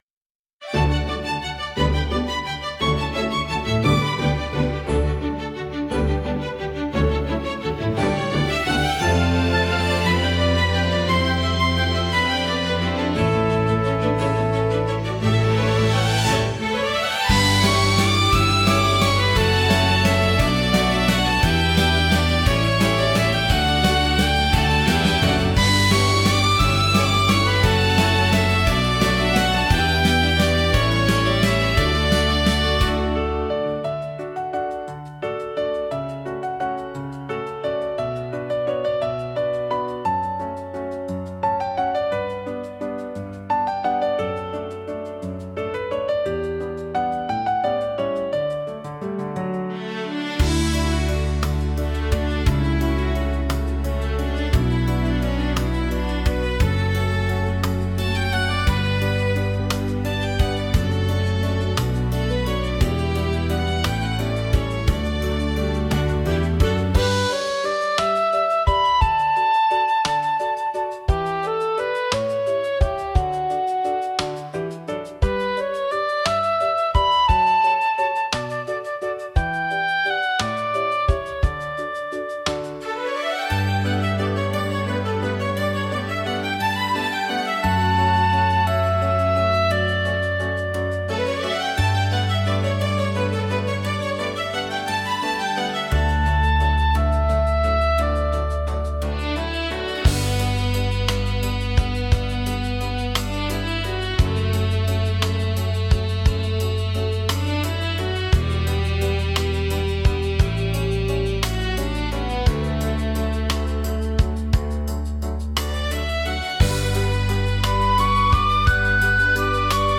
穏やかで柔らかなメロディとアレンジが、聴く人に安心感と心地よさをもたらします。
オリジナルの幸せは、幸福感や温かさを感じさせる優しい曲調が特徴のジャンルです。